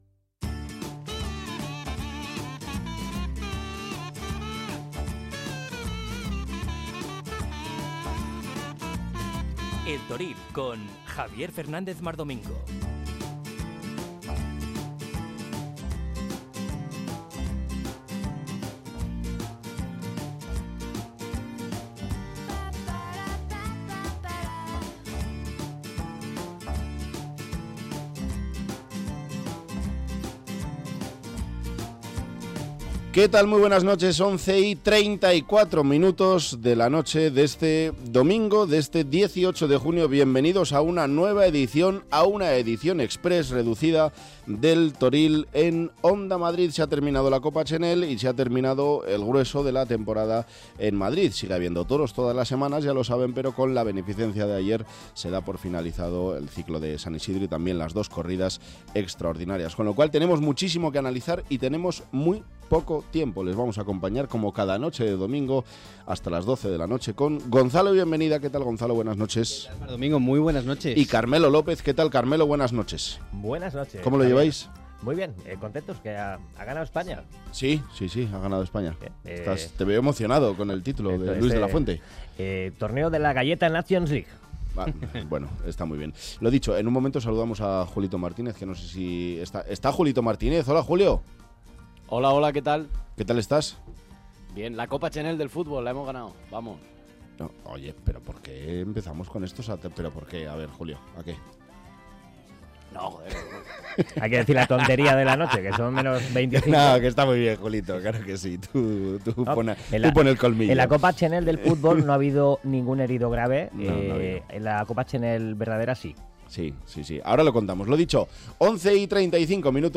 Habrá información pura y dura yentrevistas con los principales protagonistas de la semana.